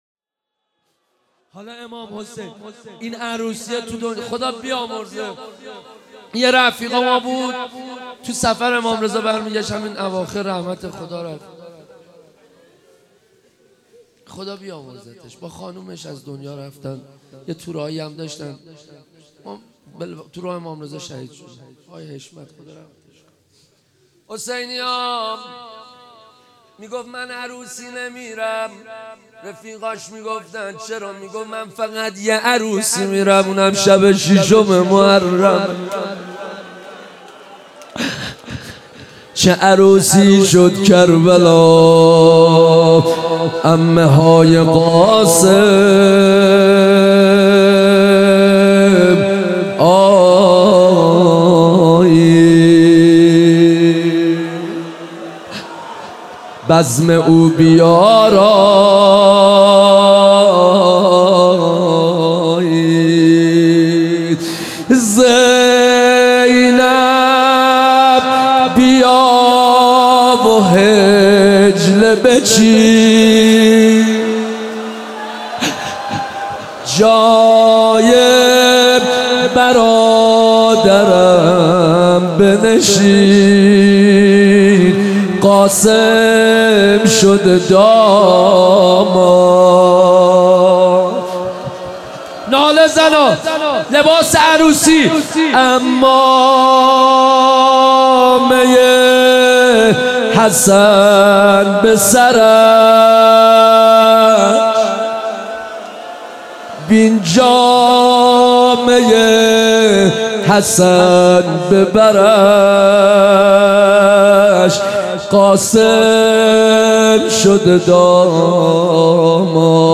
روضه حضرت قاسم